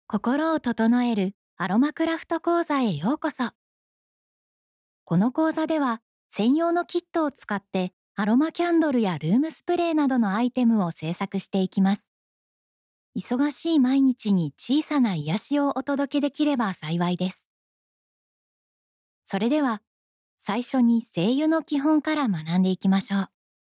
やわらかさ、透明感、温かみのある声です。
– ナレーション –
やさしい、穏やかな